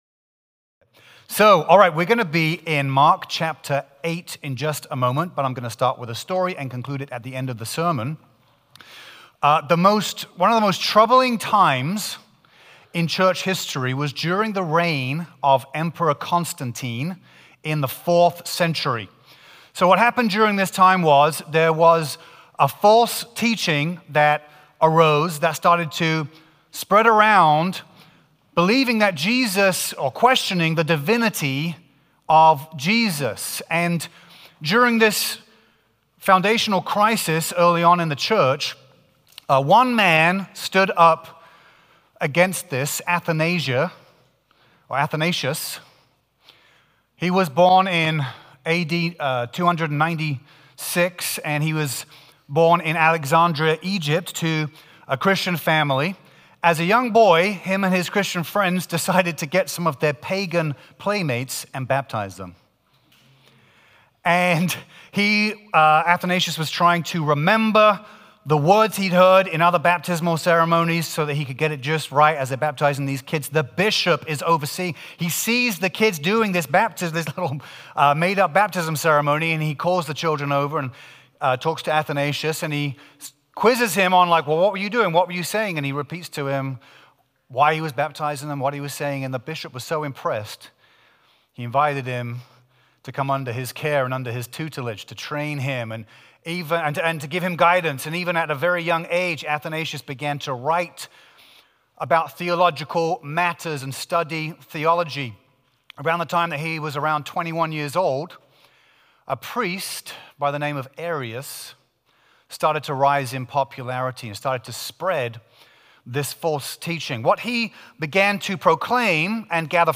Trinity Church Sermons